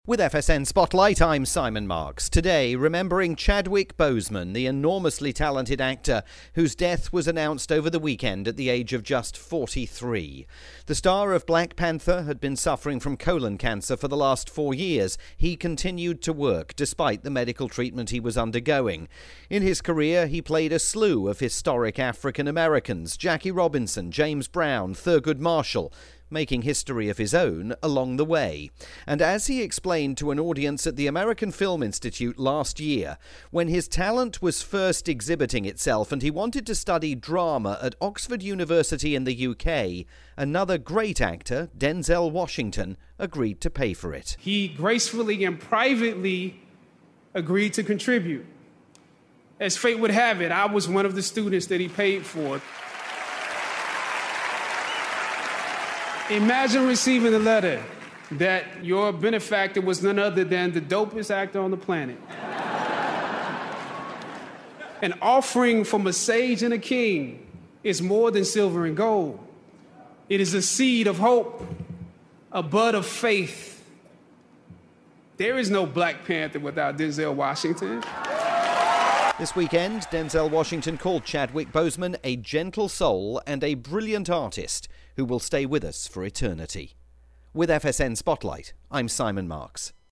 daily "FSN Spotlight" module airing on radio stations worldwide as part of Feature Story News' daily news bulletin service.